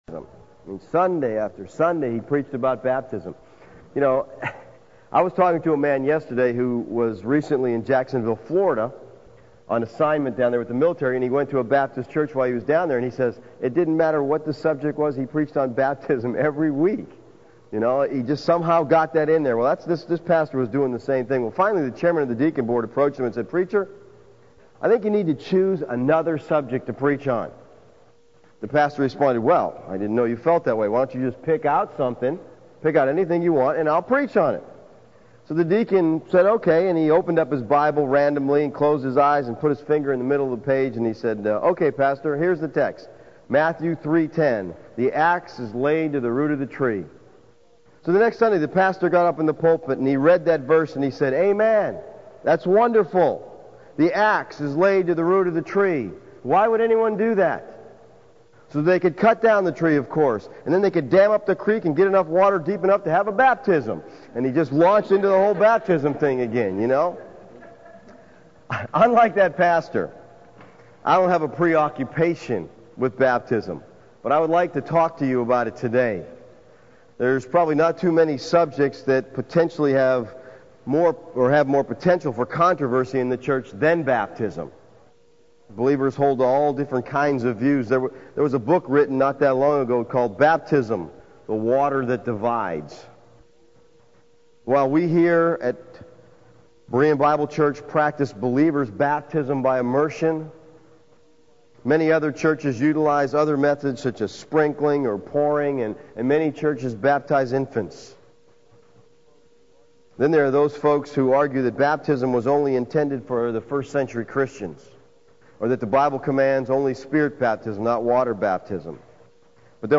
Series: Sermon on the Mount